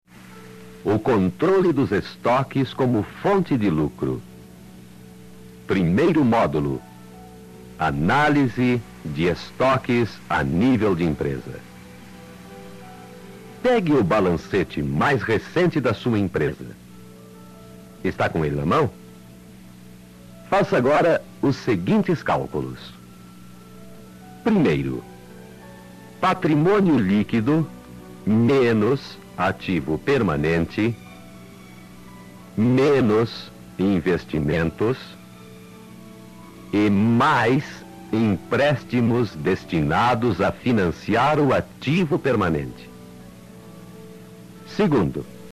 1 CD com a palestra do curso (em mp3)